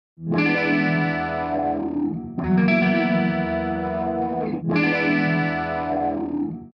Stinger - Close segment